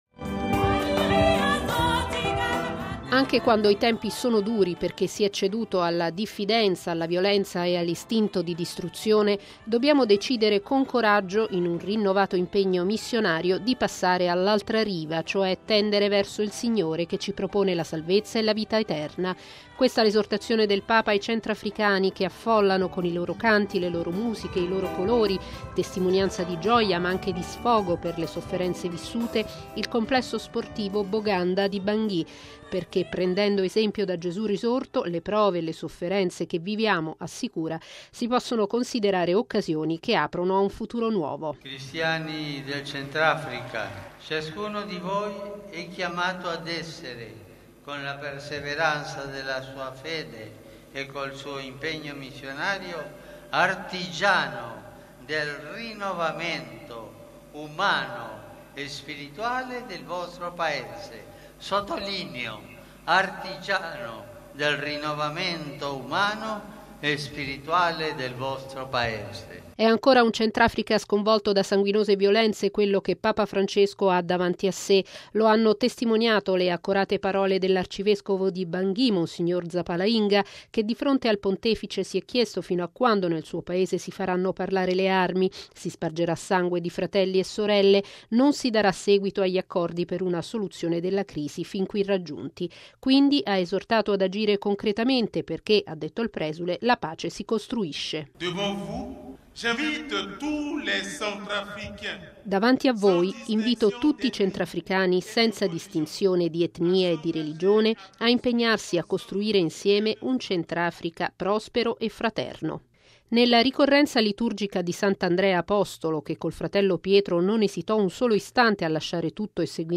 Così il Papa alla Santa Messa nel complesso sportivo Barthélémy Boganda di Bangui, accolto dall'entusiasmo della folla che ha gremito anche l’esterno dello stadio.